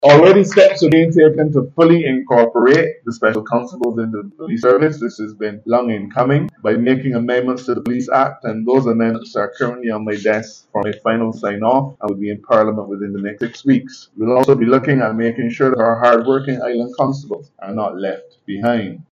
The 147th Passing-Out Parade for police recruits was held at the Regional Police training centre, yesterday.
Attorney General Dale Marshall